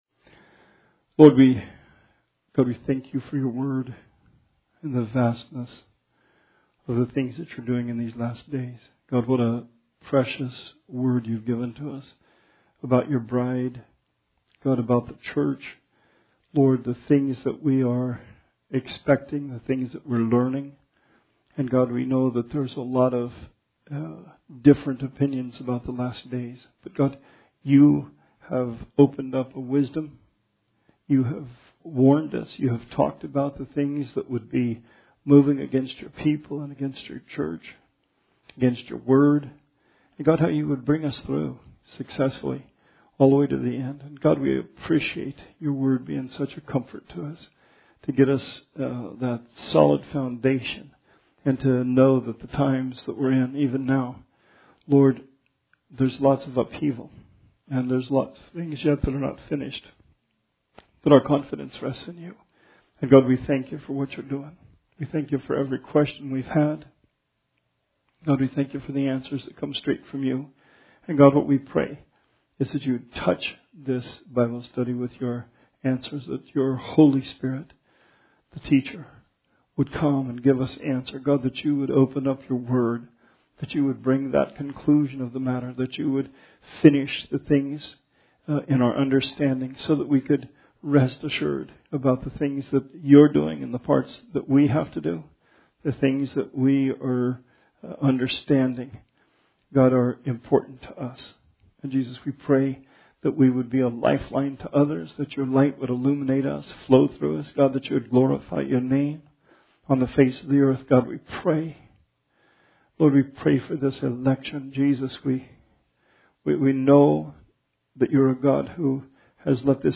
Bible Study 8/12/20